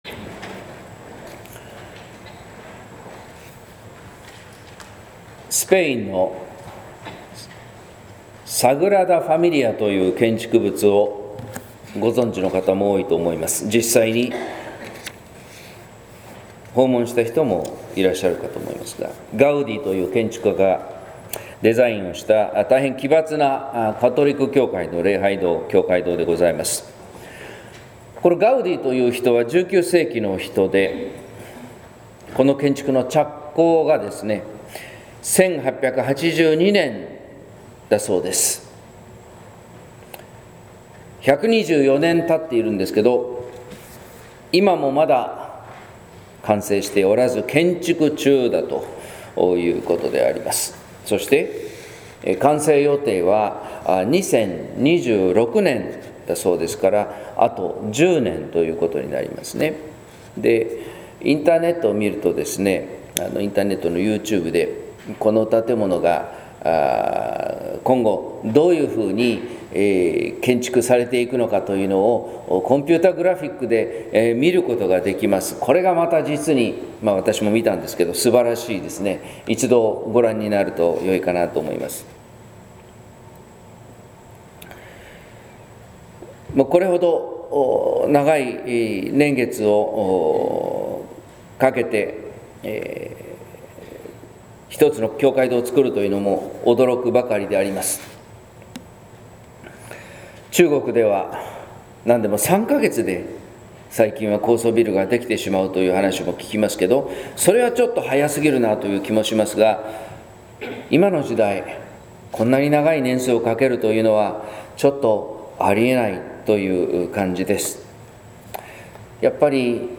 説教「みこころとのズレ」（音声版）